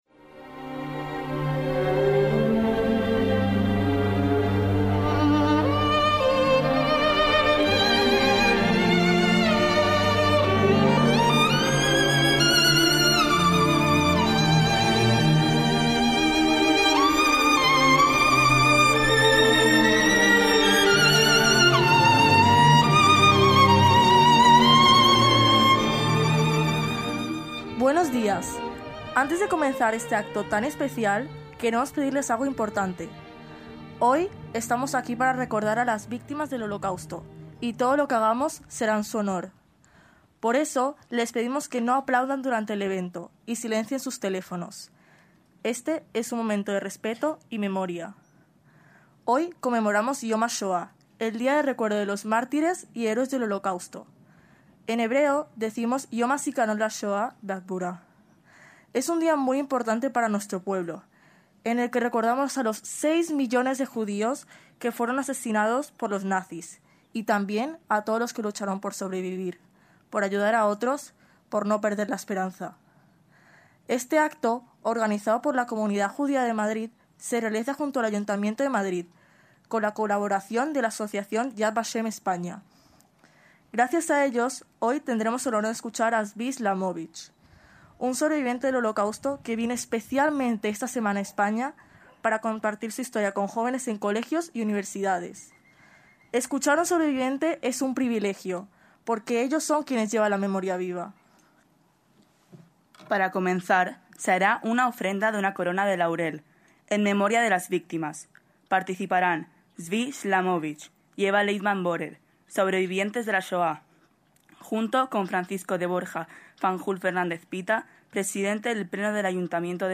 ACTOS EN DIRECTO - El domingo 27 de abril de 2025 se celebró en el Parque Juan Carlos I de Madrid el acto conmemorativo de Yom Hashoá, con presencia de autoridades y sobrevivientes del Holocausto, que reproducimos íntegramente, organizado por la Comunidad Judía de Madrid (CJM).